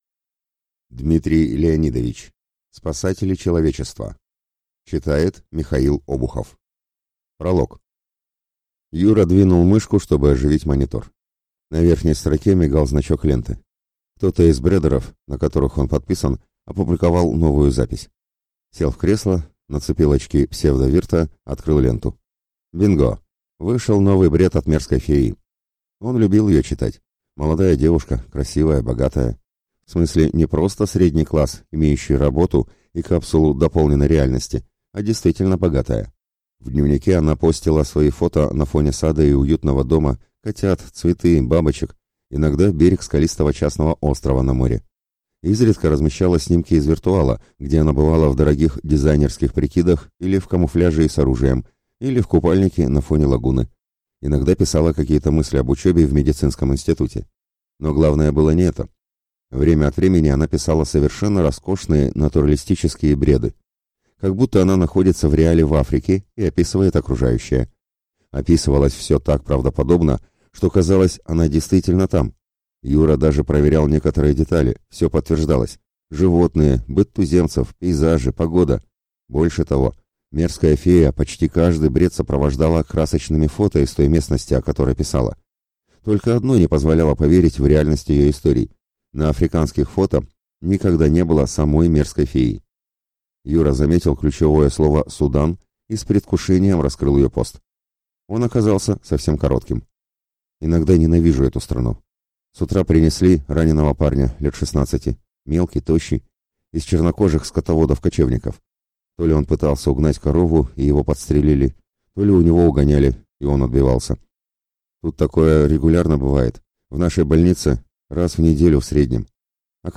Аудиокнига Спасатели человечества | Библиотека аудиокниг
Прослушать и бесплатно скачать фрагмент аудиокниги